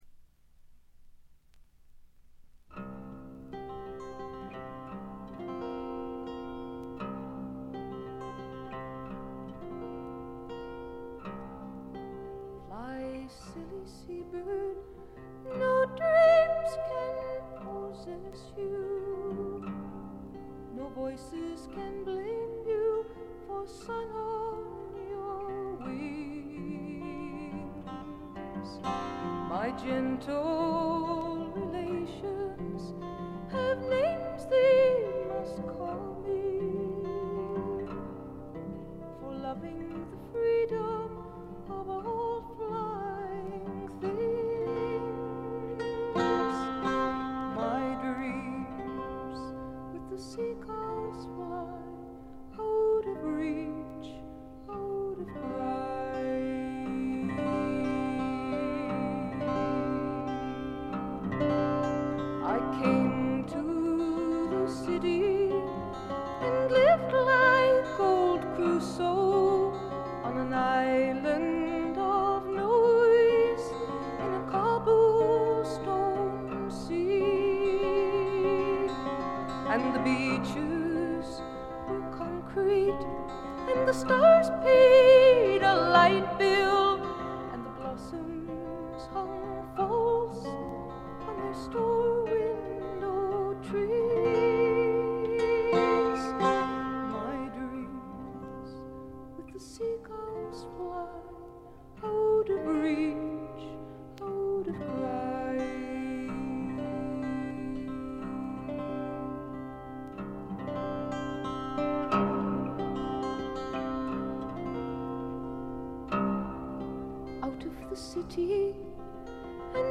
プツ音が2箇所ほど出たかな？」という程度でごくわずかなノイズ感のみ。
至上の美しさをたたえたサイケ・フォーク、アシッド・フォークの超絶名盤という見方もできます。
試聴曲は現品からの取り込み音源です。
guitar, piano, vocals